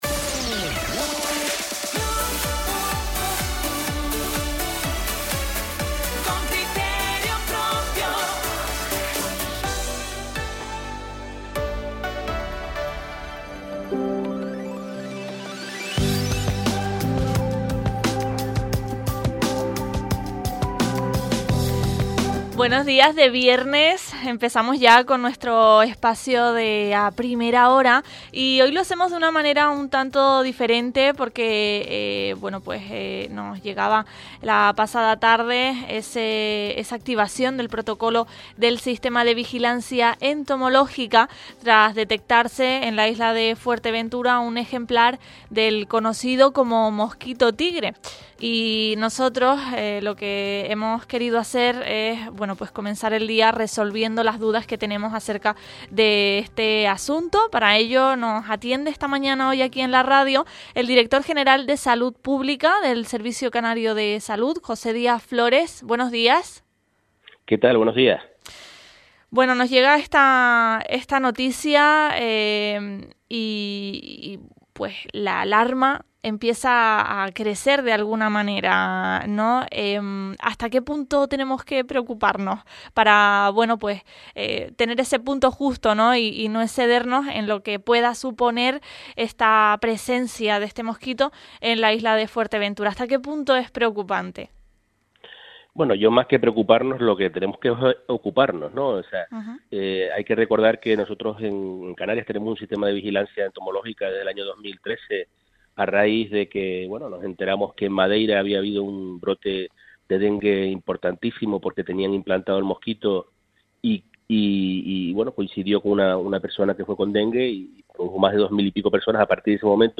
Hablamos esta mañana con el director general de Salud Pública del Servicio Canario de Salud, José Díaz Flores, sobre la presencia del mosquito tigre en Fuerteventura.
Entrevistas